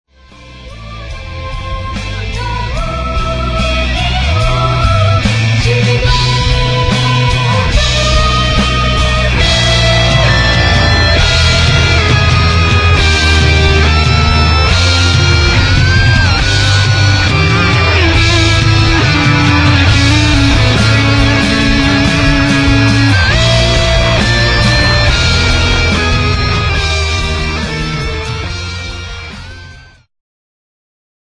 ジャンル HardRock
Progressive
Rock
シンフォニック系
ソリッドさとメロトロンサウンドが調和した、70年代クリムゾン・フォロワーへと進化。